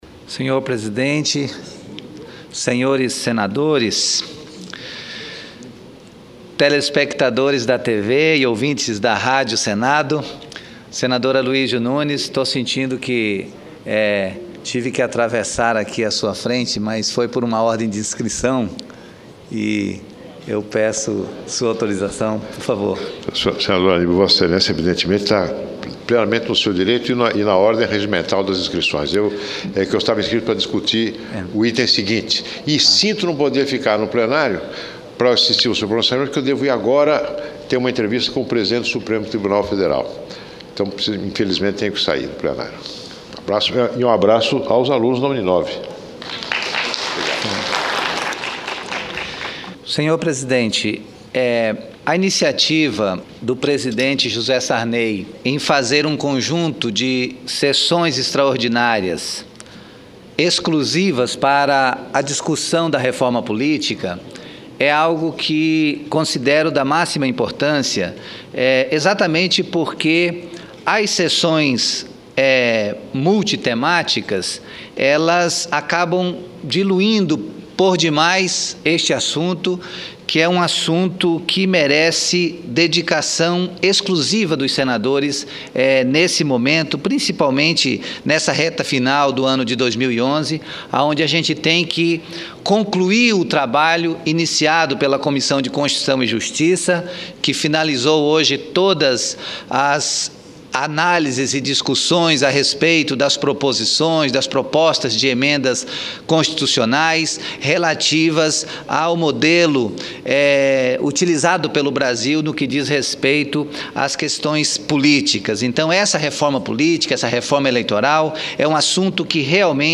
Pronunciamento do senador Anibal Diniz